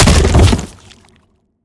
biter-death-big-4.ogg